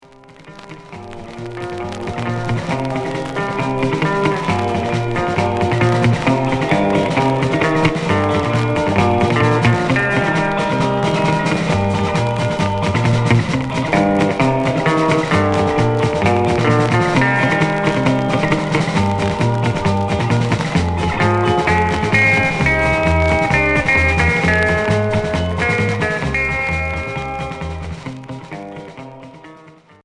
Rock instrumental Deuxième EP retour à l'accueil